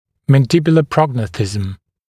[ˌmæn’dɪbjulə ‘prɔgˌnæθɪzəm][ˌмэн’дибйулэ ‘прогˌнэсизэм]мандибулярная прогнатия, нижняя прогнатия